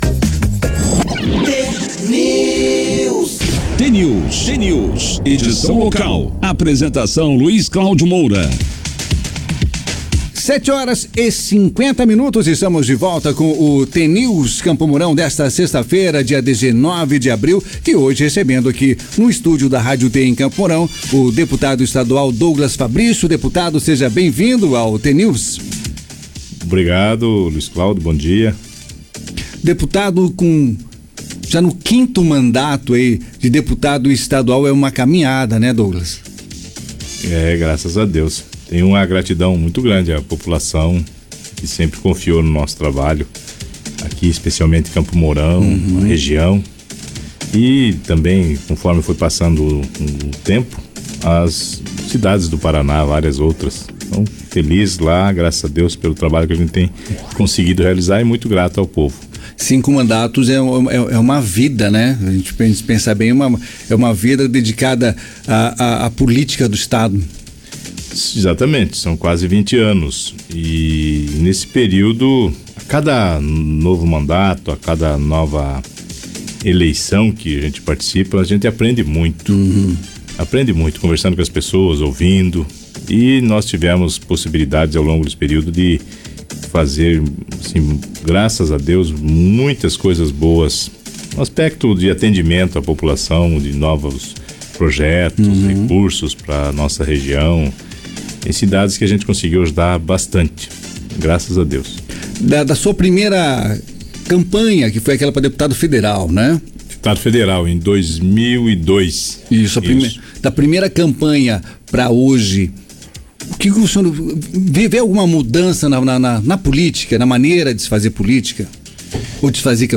O jornal T News, da Rádio T FM, nesta sexta-feira, 19, contou com a participação especial do deputado estadual Douglas Fabrício (Cidadania).